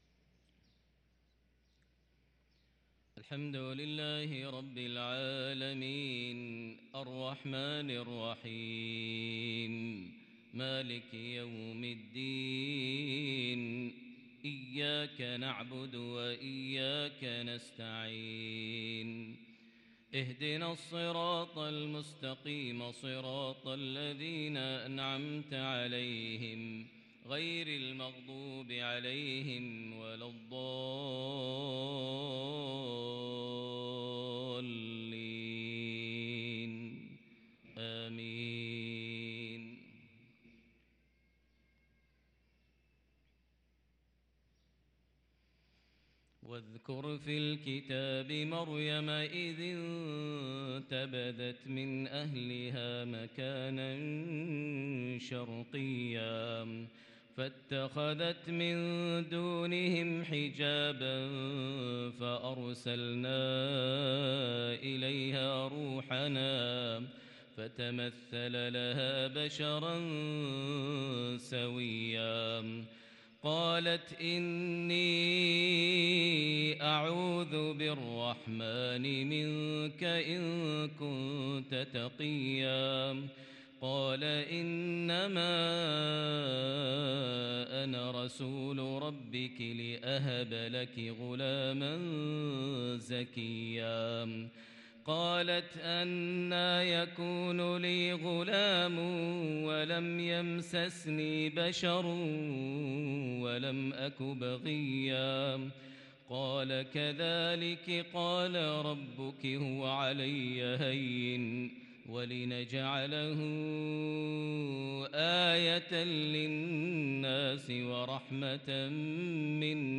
تلاوة خاشعة باكية من سورة مريم | فجر الأحد ٢٣ محرم ١٤٤٤هـ > 1444 هـ > الفروض - تلاوات ماهر المعيقلي